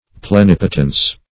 (pl[-e]*n[i^]p"[-o]*tens)